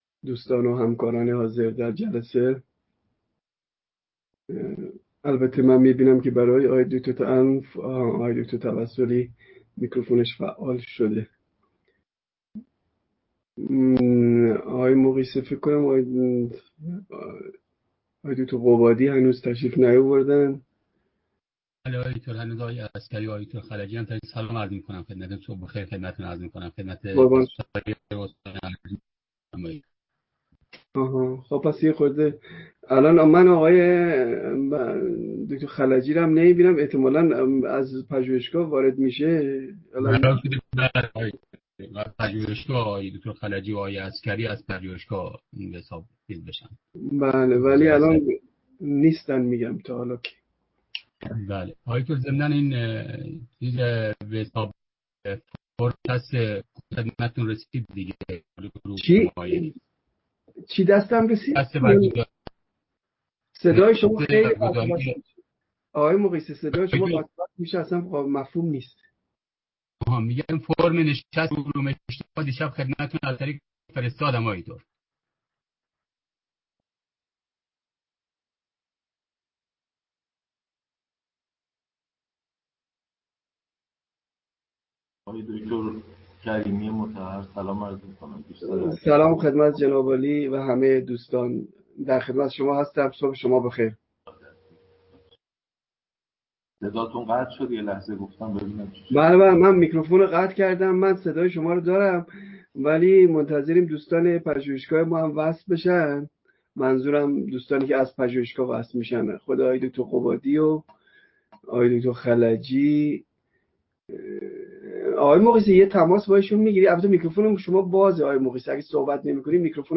سخنران : مهدی گلشنی